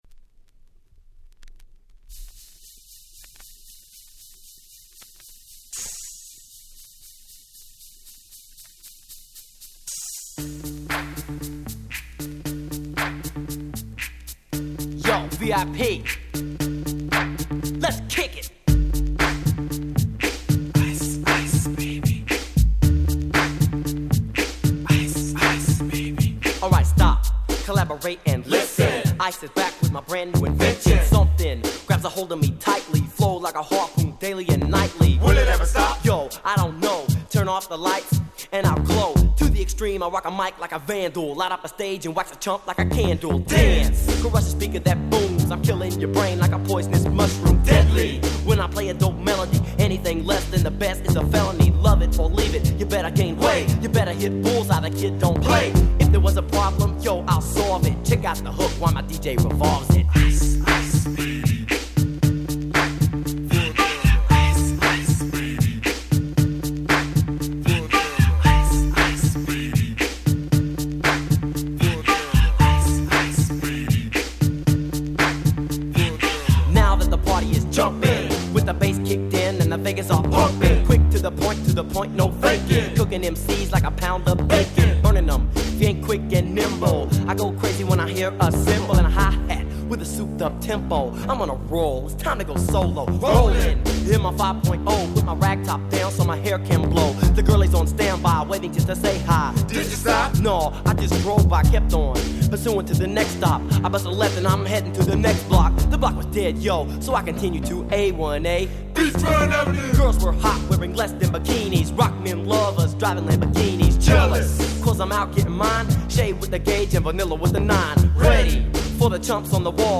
90' Super Hit Hip Hop !!